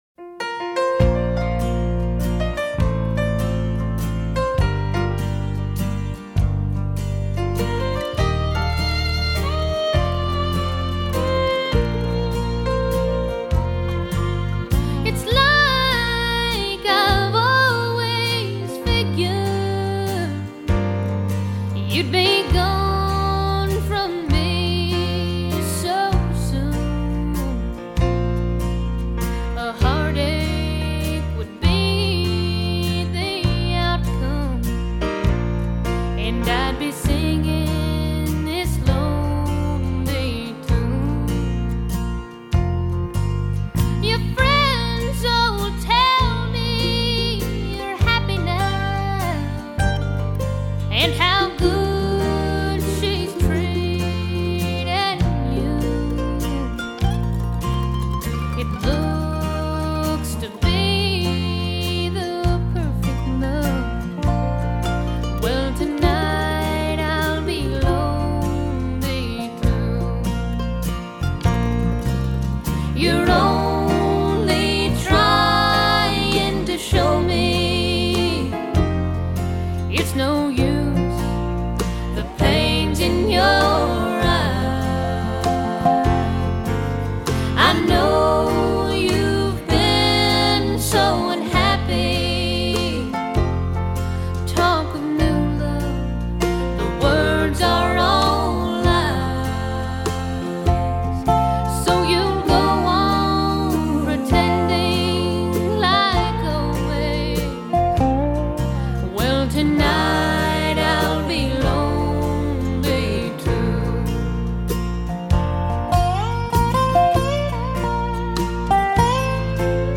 ★ 藍草音樂天后早期代表作，雙白金暢銷專輯！
★ 全音音樂網站五顆星無條件推薦，《滾石》雜誌四星高評，歌曲首首動聽，錄音鮮活絲滑！